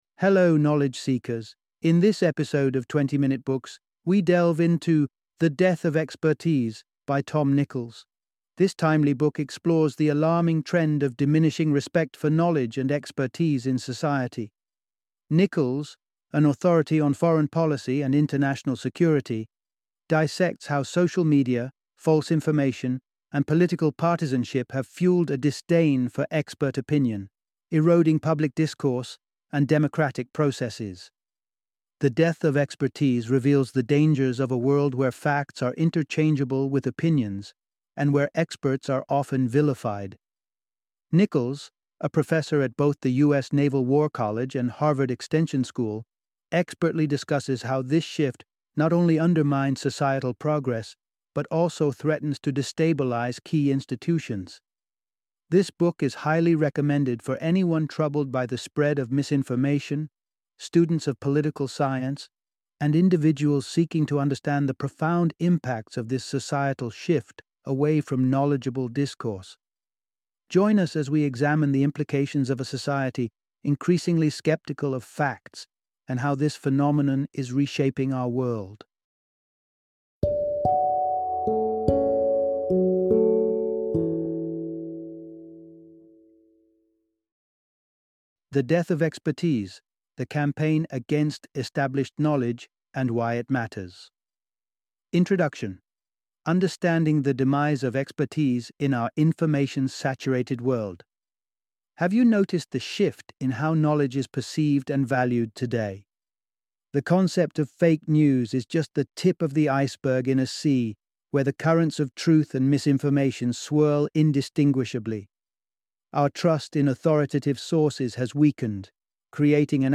The Death of Expertise - Audiobook Summary